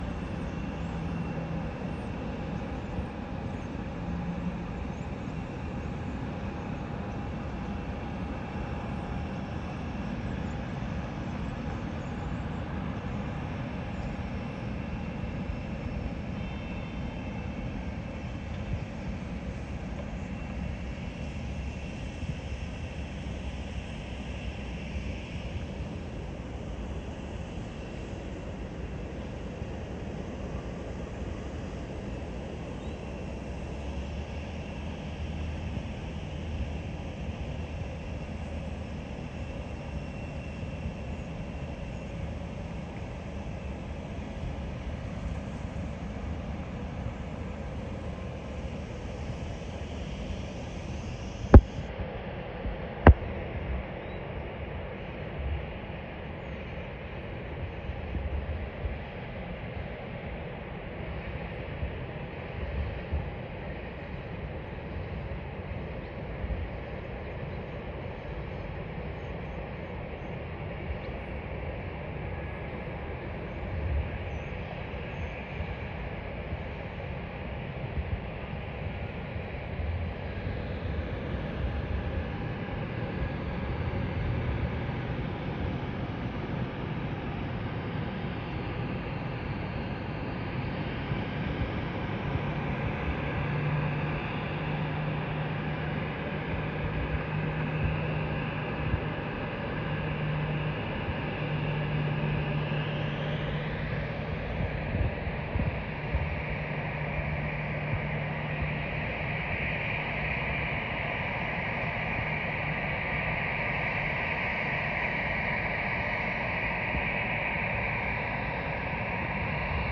Plane Spotting At Fll